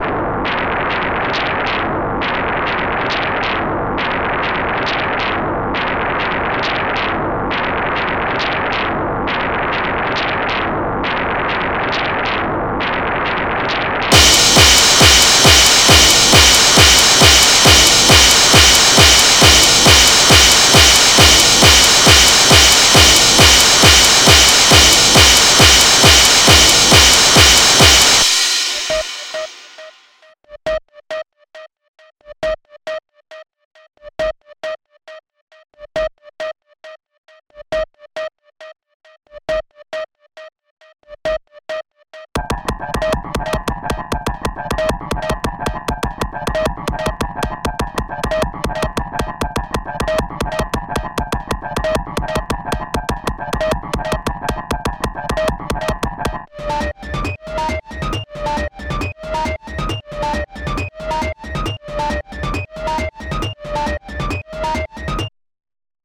From an atonal, experimental, avant garde view it’s really great. My only suggestion is maybe don’t clip the audio and try to normalize it through the tracks as it’ll make for an easier listening experience in that regard rather than blow someone’s ears out :slightly_smiling_face: